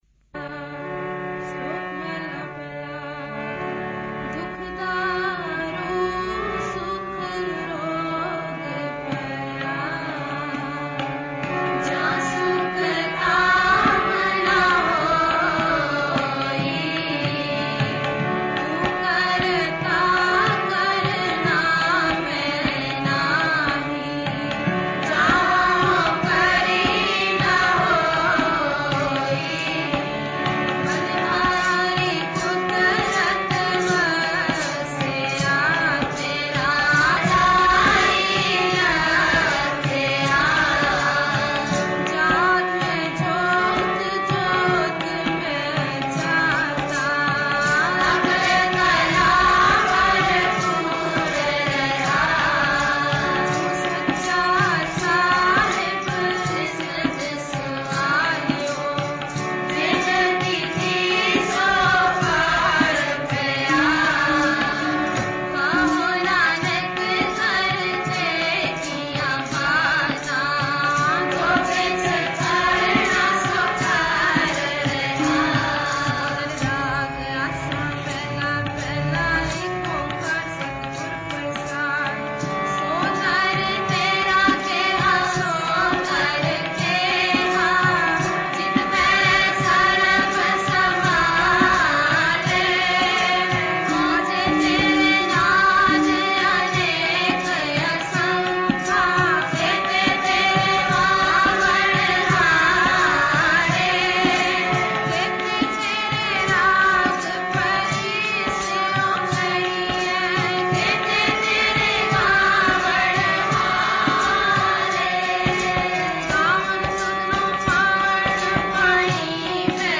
Genre: Gurbani Ucharan Album Info